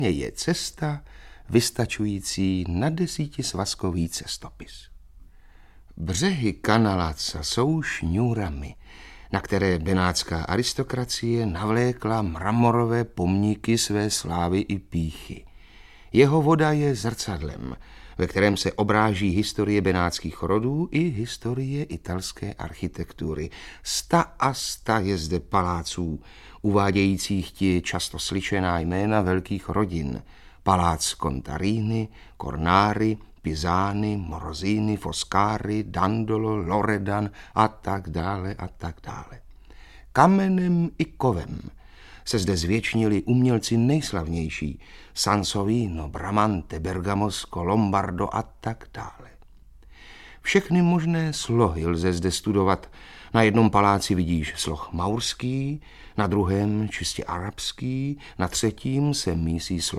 Audiobook
Read: Václav Postránecký